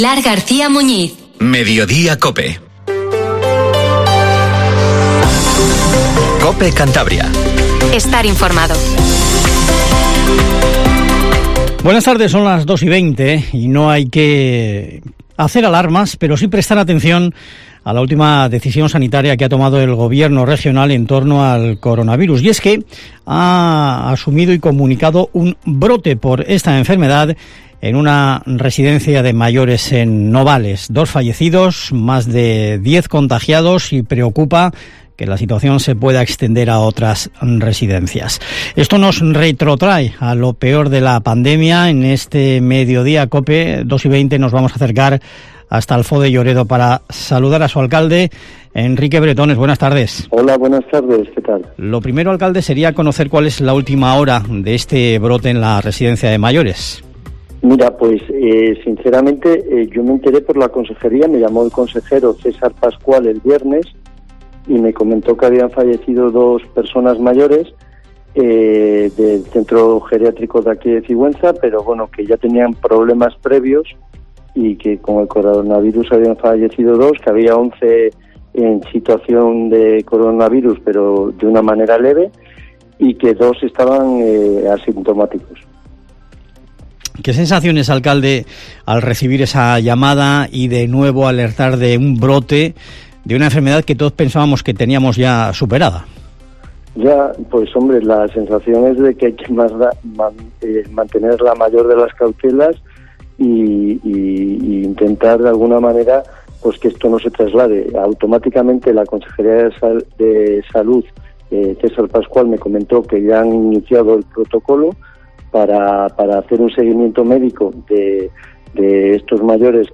Informativo MEDIODIA COPE CANTABRIA 14:20